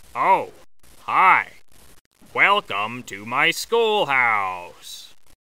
Голос Балди и звуковые эффекты из игры для монтажа видео в mp3 формате